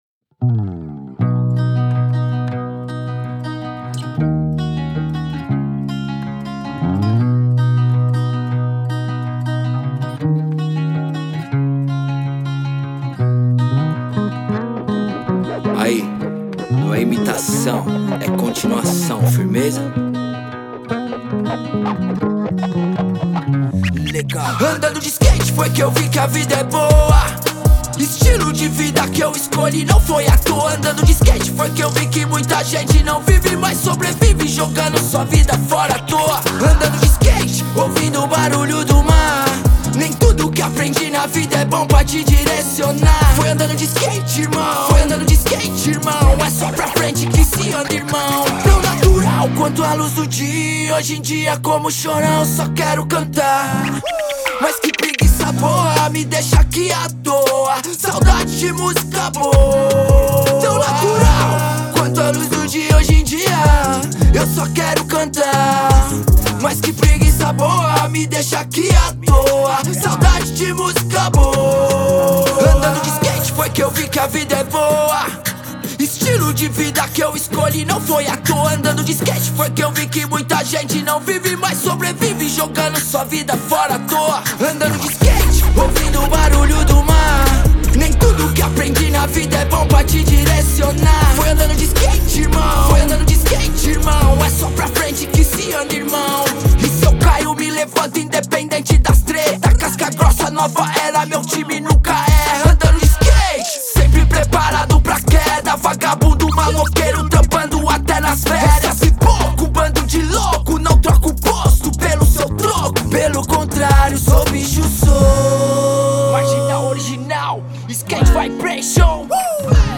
EstiloHip Hop / Rap